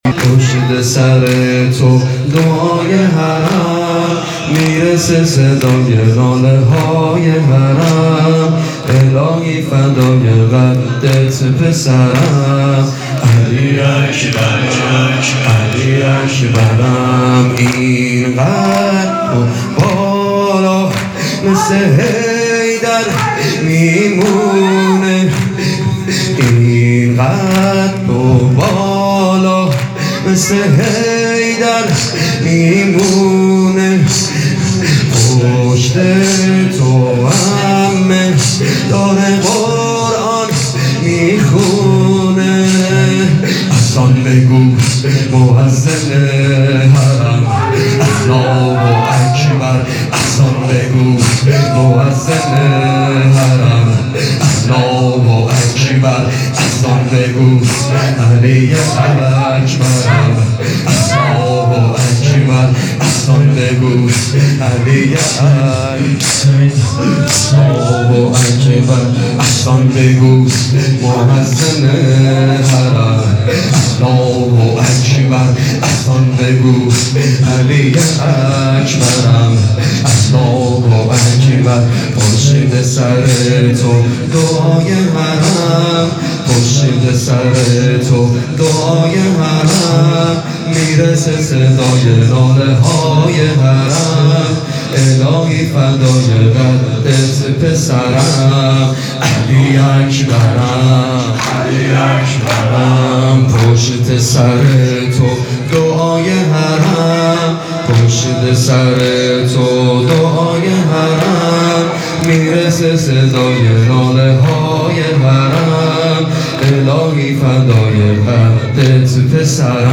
زمینه شب هشتم محرم الحرام۱۴۴۱_حضرت علی اکبر
دهه اول محرم98